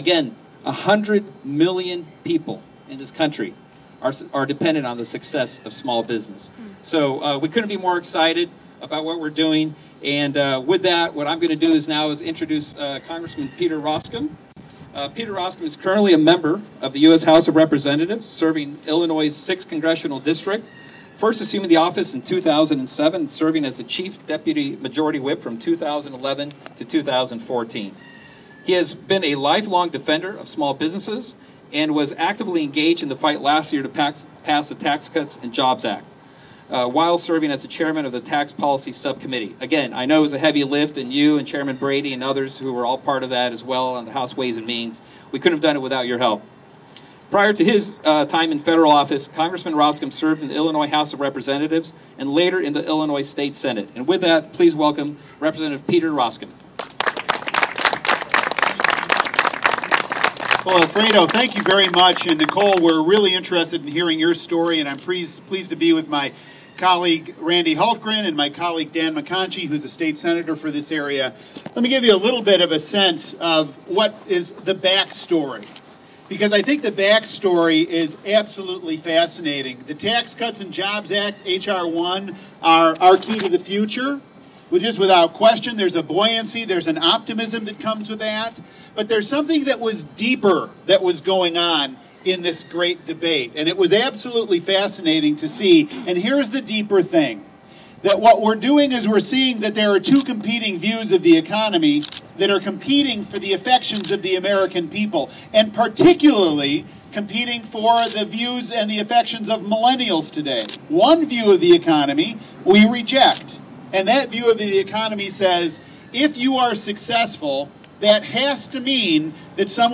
WAUCONDA – Monday morning, US Congress members, a state Senator, small business owners and a network of job creators met at HM Manufacturing in Wauconda to celebrate how federal tax cuts are helping both employers and employees with new opportunities, raises and bonuses.